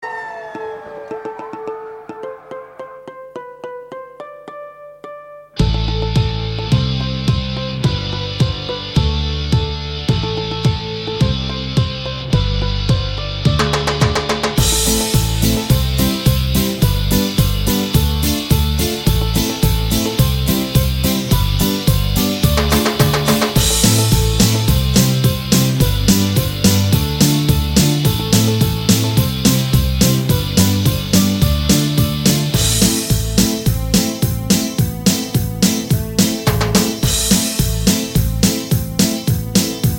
инструментальные
без слов , рок